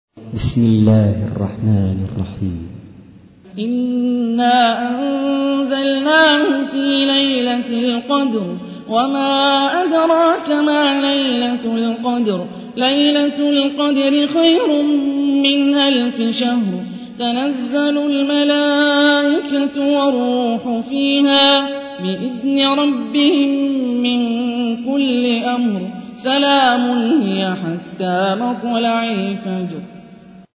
تحميل : 97. سورة القدر / القارئ هاني الرفاعي / القرآن الكريم / موقع يا حسين